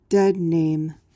PRONUNCIATION: (DED-naym) MEANING: noun: The former name of a person. verb tr.: To call someone using their former name.